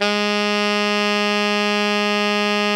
ALTO  FF G#2.wav